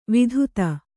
♪ vidhuta